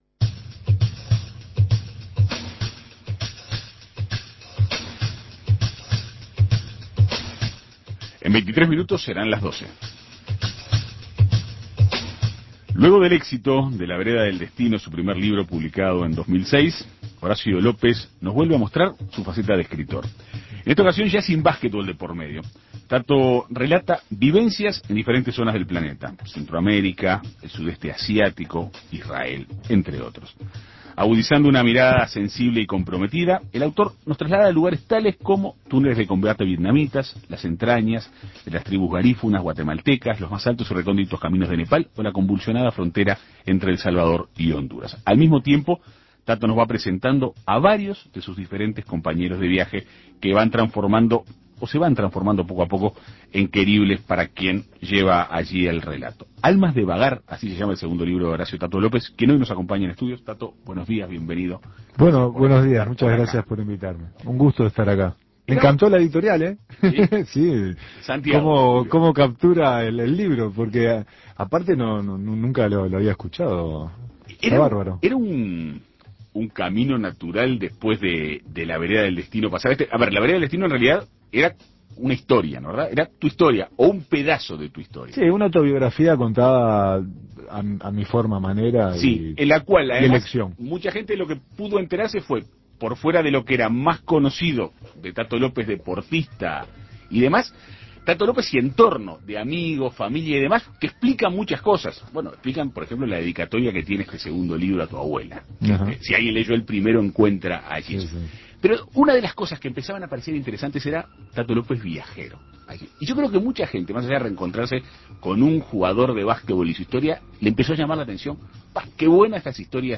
En Perspectiva Segunda Mañana dialogó con el ex deportista.